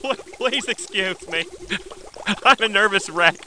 00940_Sound_ship.mp3